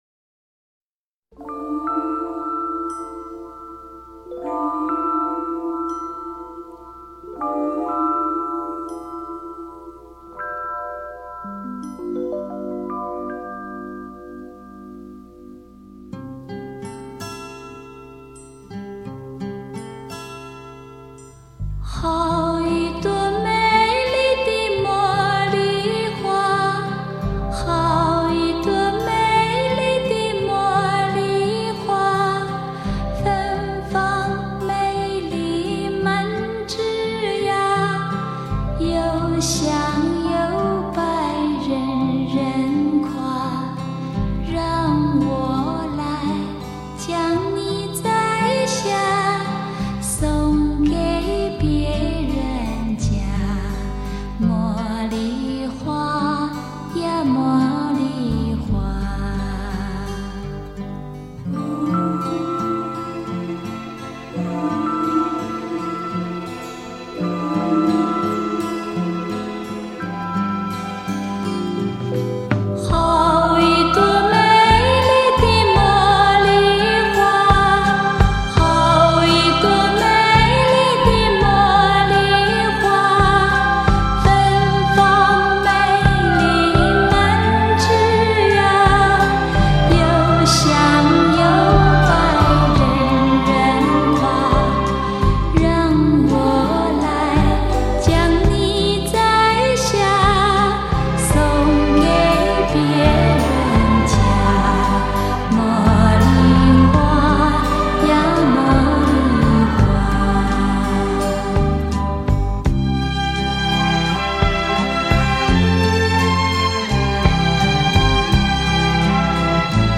中国民歌